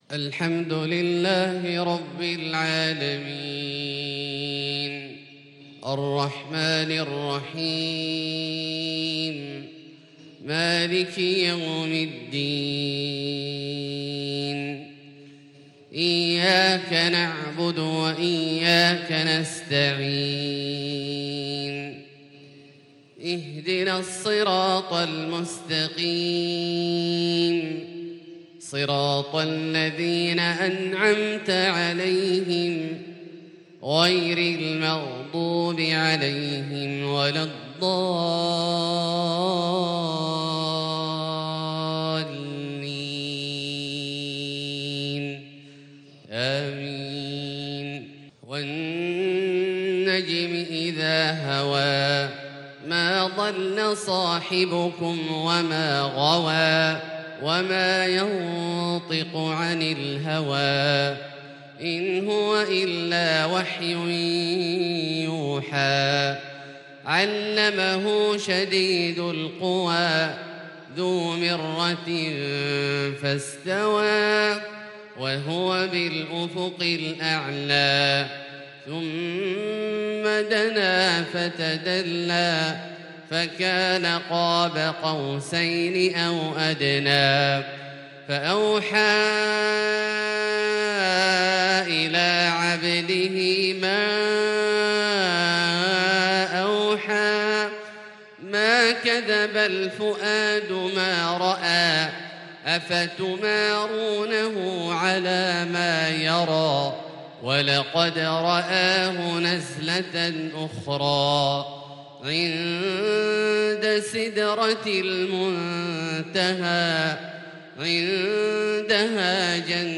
صلاة الفجر للقارئ عبدالله الجهني 8 جمادي الأول 1442 هـ
تِلَاوَات الْحَرَمَيْن .